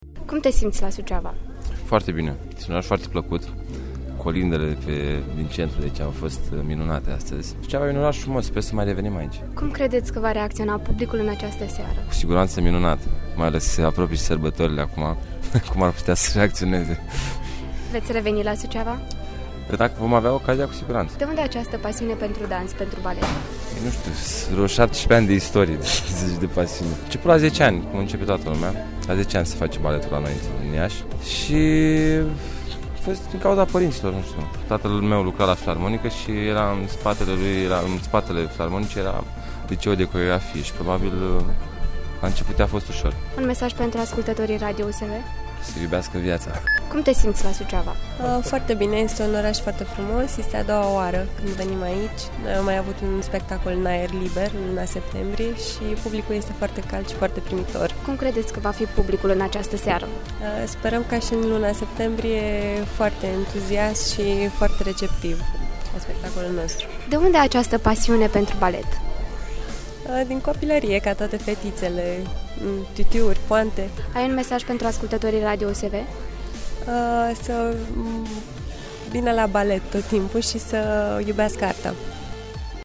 interviu-solisti-opera.mp3